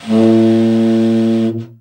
BRA_TEN SFT    1.wav